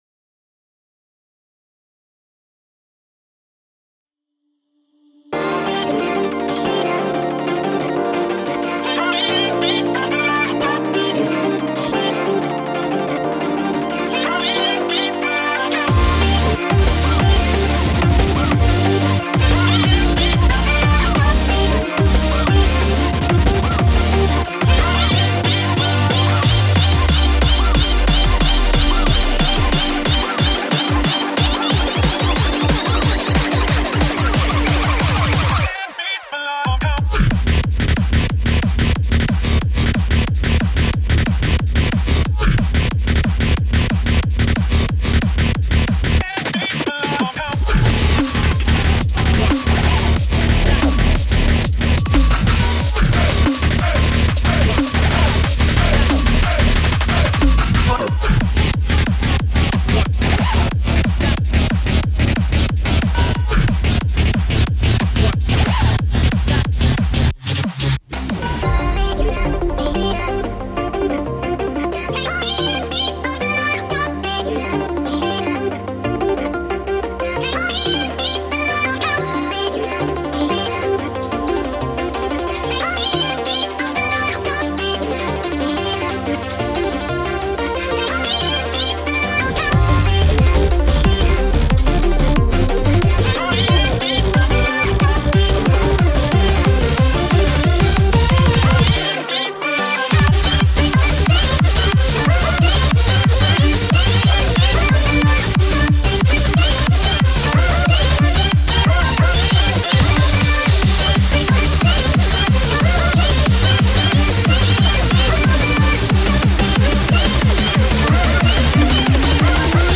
BPM91-182
Audio QualityPerfect (High Quality)